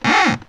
Index of /90_sSampleCDs/E-MU Producer Series Vol. 3 – Hollywood Sound Effects/Miscellaneous/WoodscrewSqueaks
WOOD SQUEA04.wav